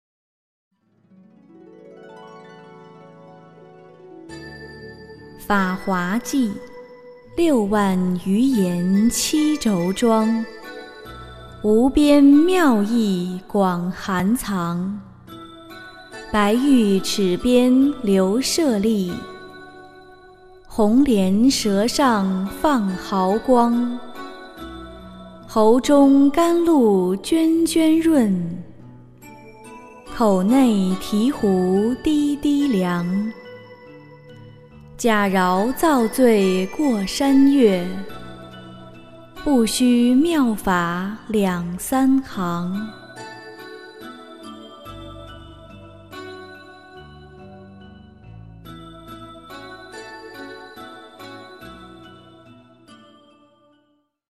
诵经
佛音 诵经 佛教音乐 返回列表 上一篇： 发菩提心经论卷上 下一篇： 功德宝山神咒 相关文章 南无当来下生弥勒尊佛--佚名 南无当来下生弥勒尊佛--佚名...